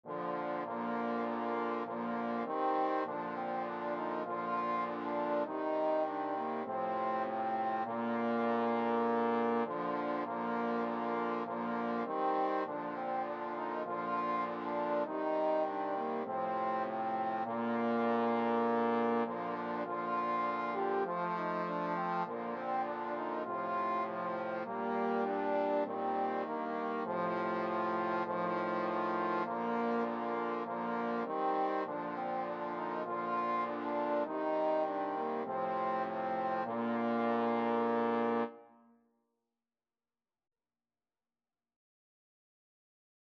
Free Sheet music for Trombone Quartet
Trombone 1Trombone 2Trombone 3Trombone 4
"O Little Town of Bethlehem" is a popular Christmas carol.
4/4 (View more 4/4 Music)
Bb major (Sounding Pitch) (View more Bb major Music for Trombone Quartet )